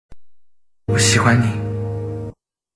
偶像特效我喜欢你音效_人物音效音效配乐_免费素材下载_提案神器